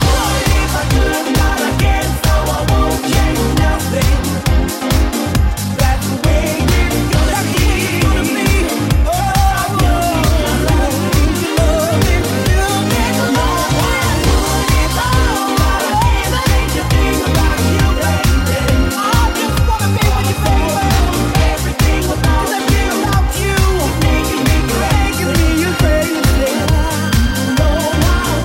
Genere: dance, house, electro, remix, 2009